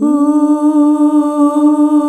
UUUUH   D.wav